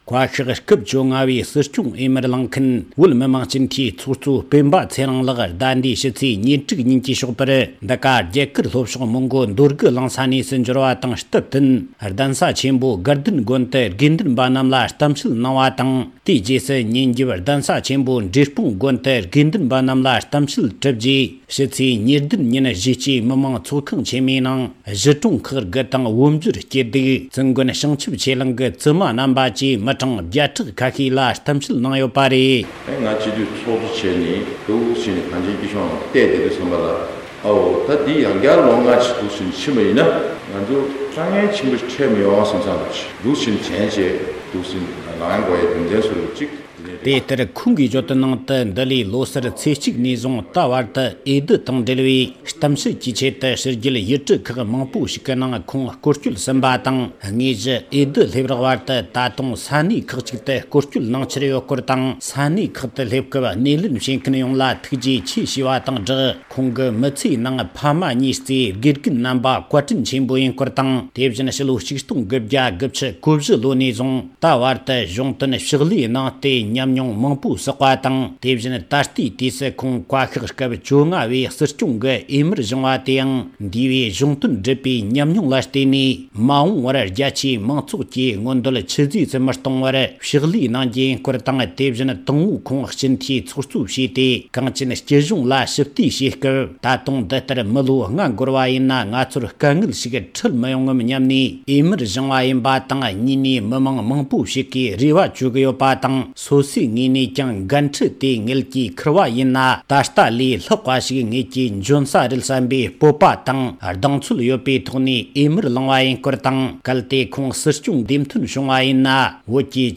སྲིད་སྐྱོང་འོས་མི་སྤེན་པ་ཚེ་རིང་མཆོག་རྒྱ་གར་ལྷོ་ཕྱོགས་སུ་ཕེབས་པ། ༢༠༡༦ལོའི་སྲིད་སྐྱོང་འོས་མི་སྤྱི་འཐུས་ཚོགས་གཙོ་སྤེན་པ་ཚེ་རིང་མཆོག་ས་གནས་མི་མང་ཚོགས་ཁང་ནང་གསུང་བཤད་གནང་བ།
སྒྲ་ལྡན་གསར་འགྱུར།